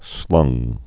(slŭng)